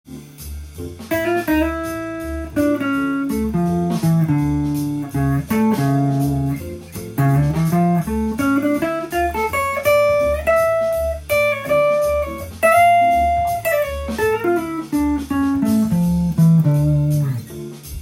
ギターソロを弾く場合　Ｄｍと書いてあるコード進行で
ＤｍＭ７（９）をそのまま弾くとかなりおしゃれでカッコいい感じになります。